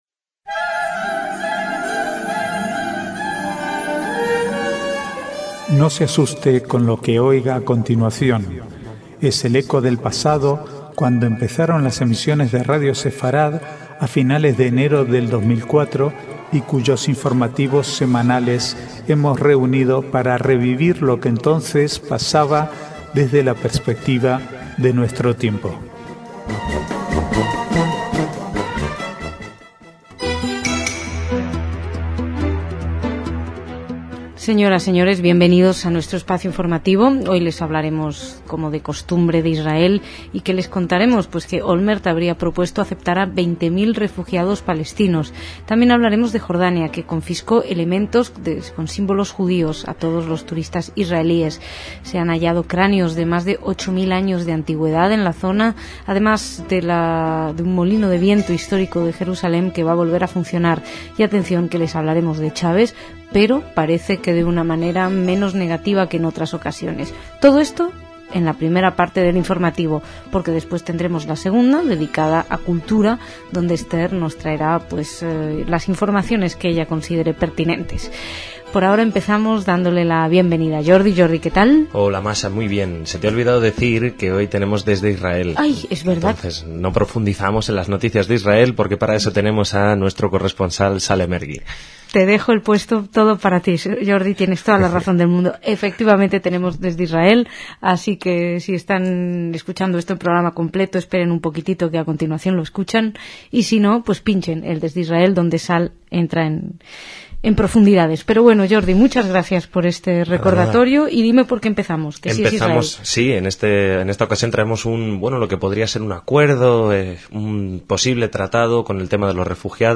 Es el eco del pasado, cuando empezaron las emisiones de Radio Sefarad a finales de enero de 2004 y cuyos informativos semanales hemos reunido para revivir lo que entonces pasaba desde la perspectiva de nuestro tiempo.